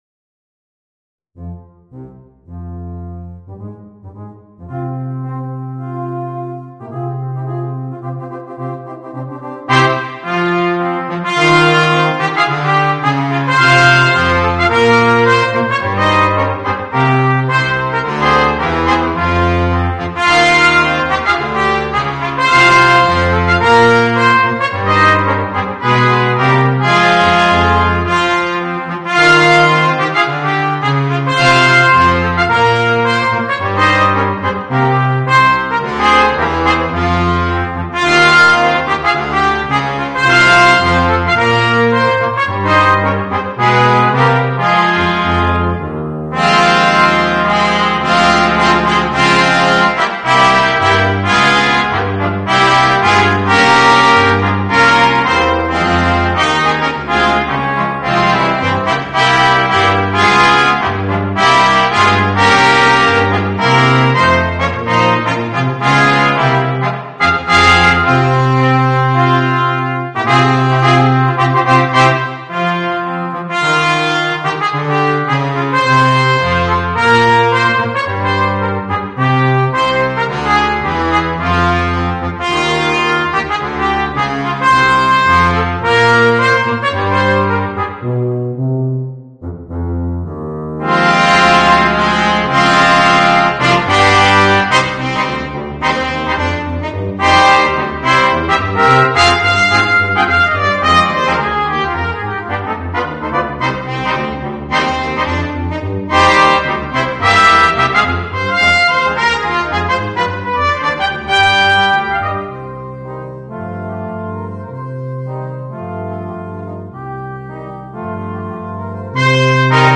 Voicing: 3 Trumpets and 2 Trombones